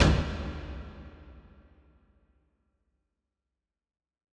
Taiko Drum 2 (Coldest Winter).wav